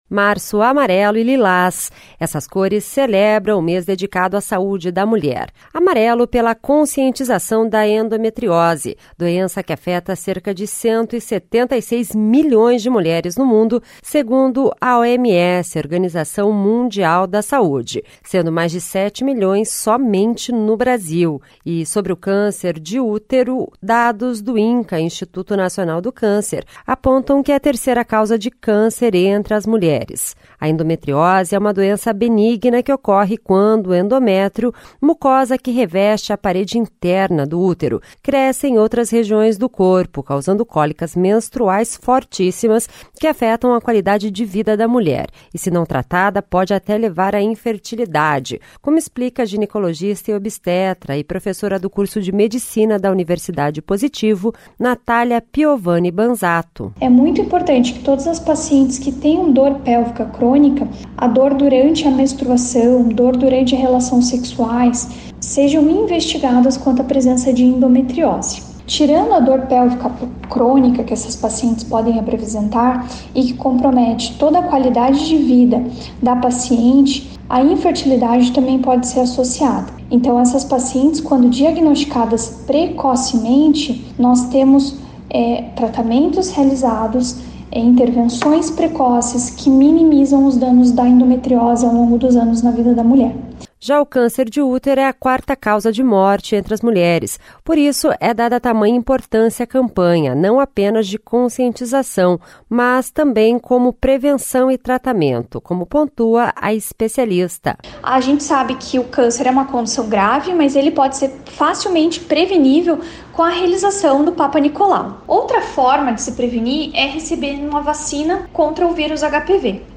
Especialista fala sobre a importância do diagnostico precoce para melhora da qualidade de vida e tratamento das doenças.